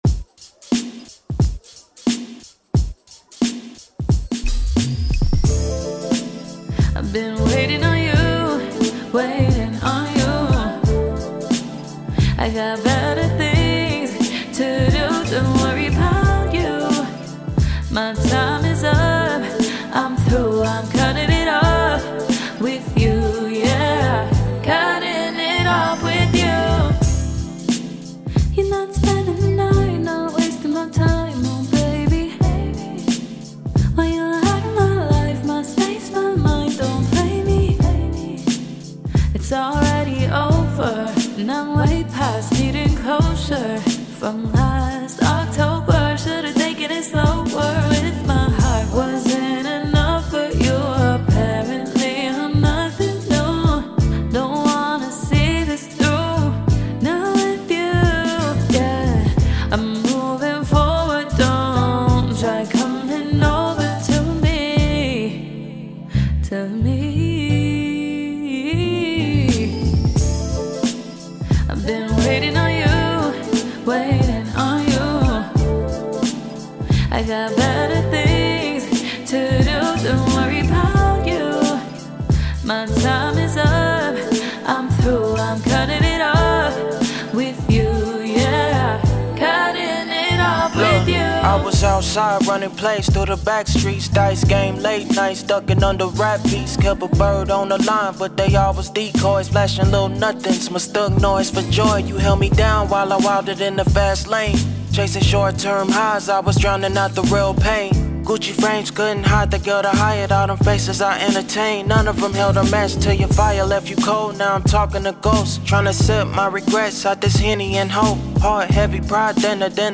90s, R&B, Pop
A minor